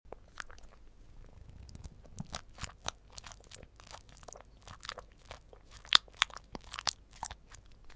Cute Cat ASMR 🐱 Relaxing Sound Effects Free Download